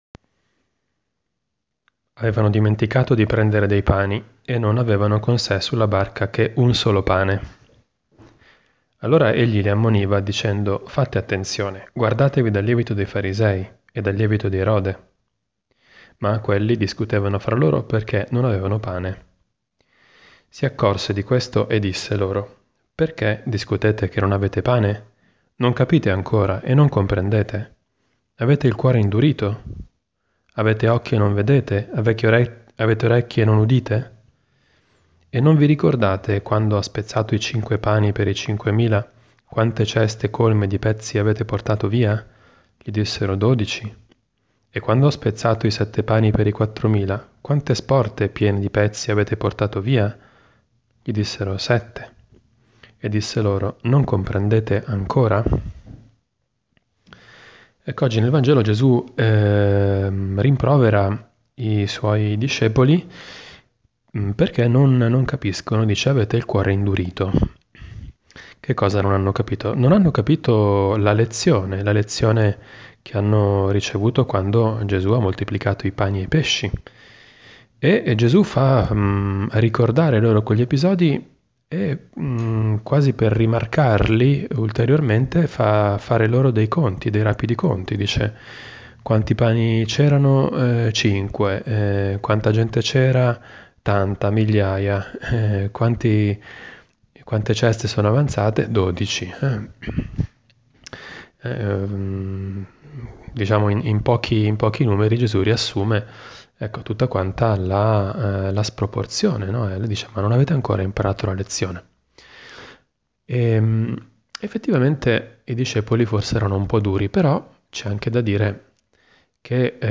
Commento al vangelo (Mc 8,14-21) del 13 febbraio 2018, martedì della VI settimana del Tempo Ordinario.